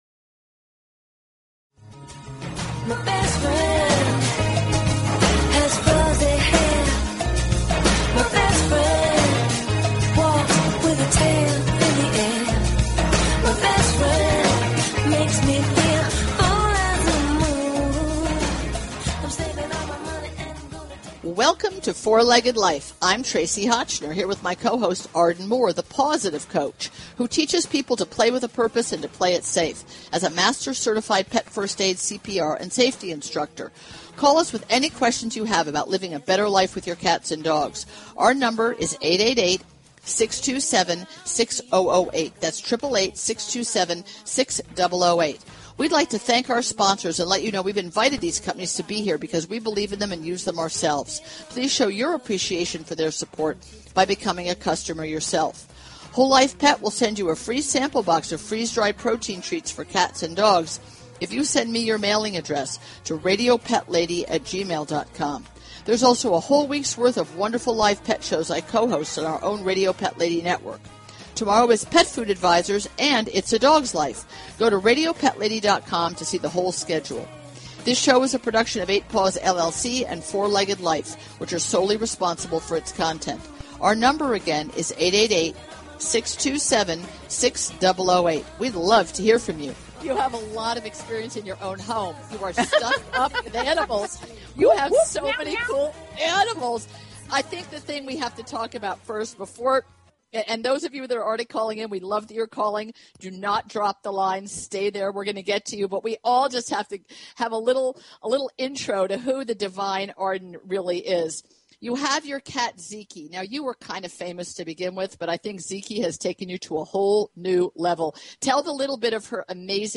Talk Show Episode, Audio Podcast, Four-Legged_Life and Courtesy of BBS Radio on , show guests , about , categorized as